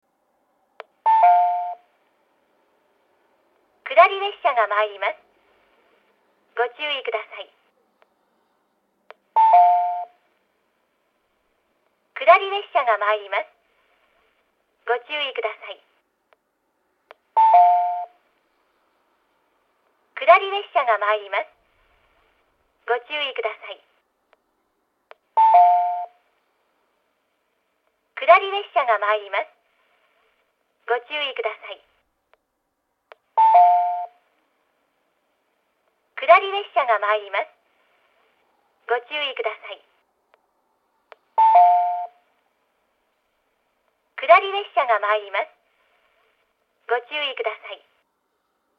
tomai-2bannsenn-sekkinn.mp3